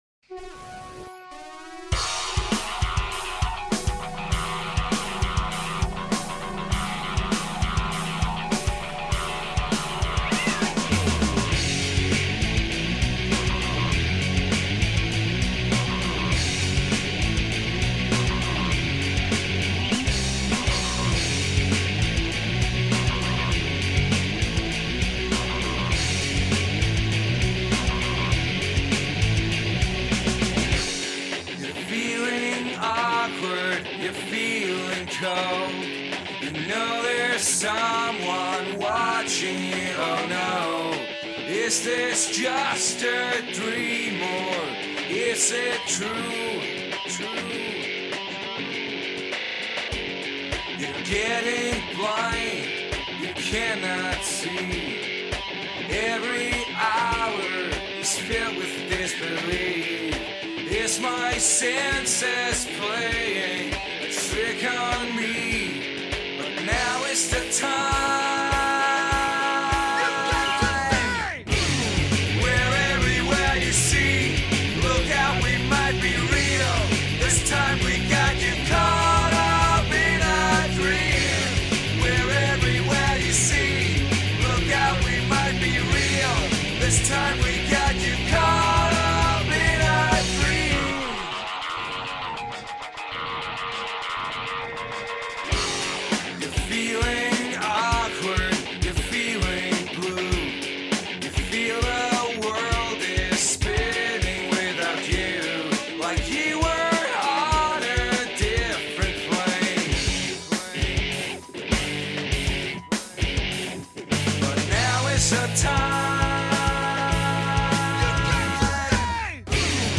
Demo Version (MP3)
Vocals